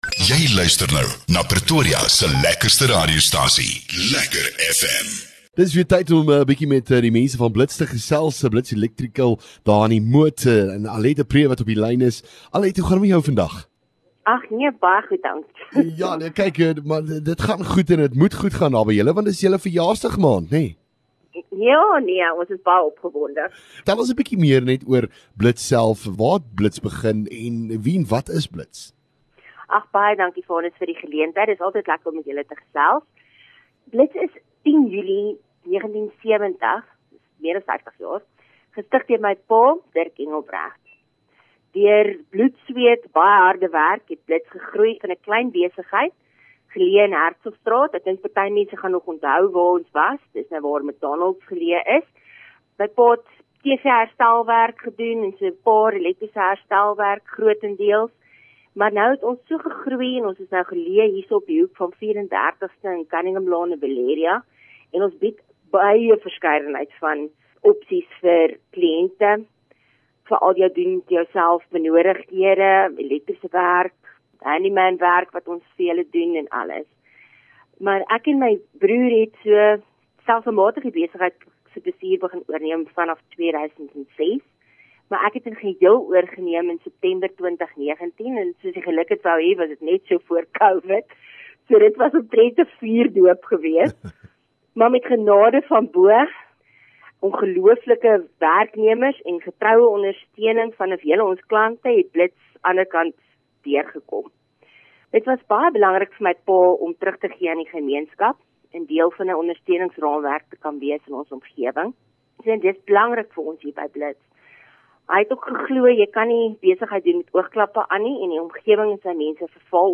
LEKKER FM | Onderhoude 3 Jul Blits Elektrisiëns